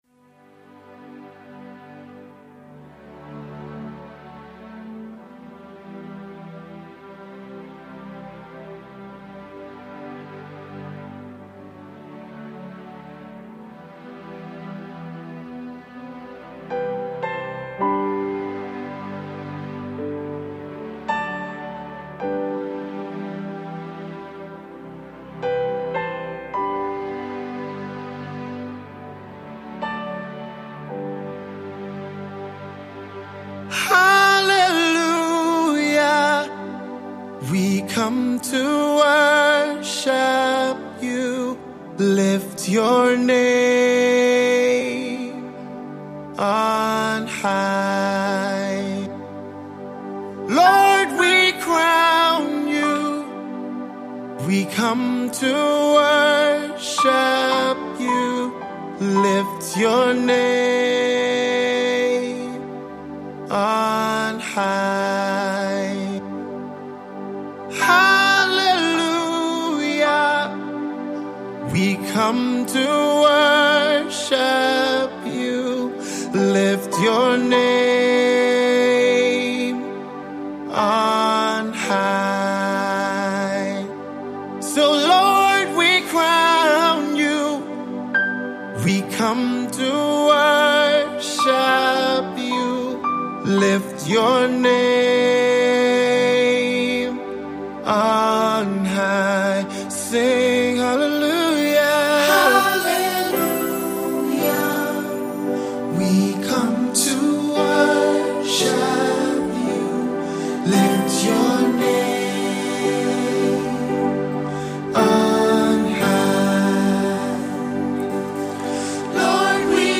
Such a powerful worship song!